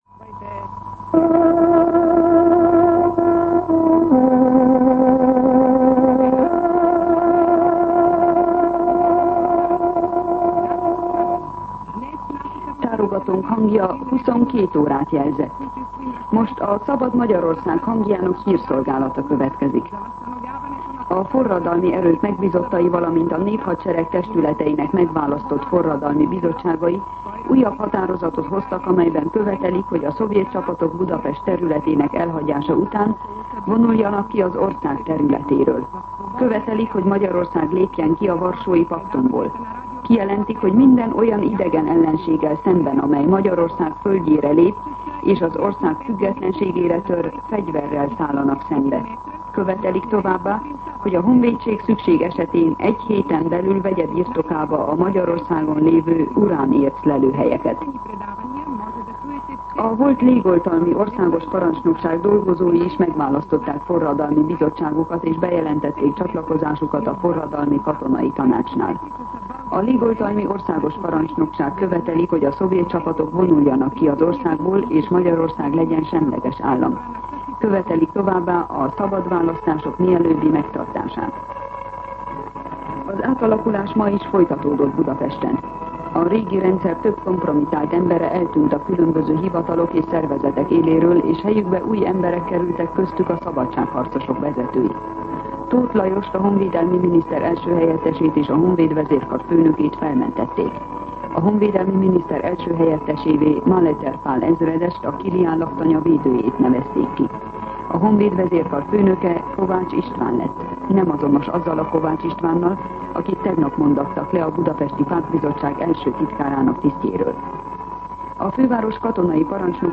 22:00 óra. Hírszolgálat